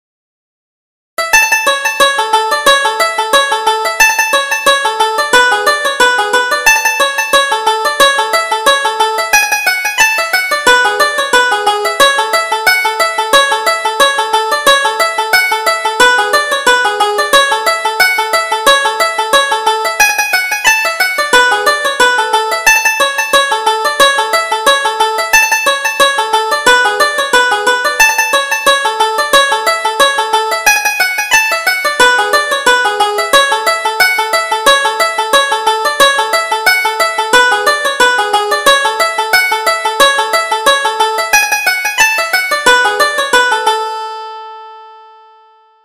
Reel: Duffy the Dancer